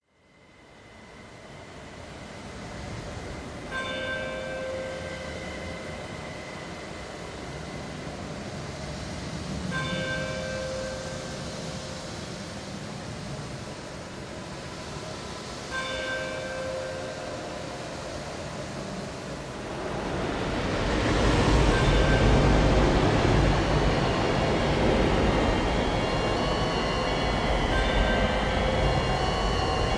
Halloween Party Soundscape
HalloweenParty.mp3